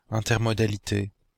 Ääntäminen
Ääntäminen Belgique, Brabant wallon: IPA: /ɛ̃.tɛʁ.mɔ.da.li.te/ Haettu sana löytyi näillä lähdekielillä: ranska Käännös Adjektiivit 1. intermodal Suku: f .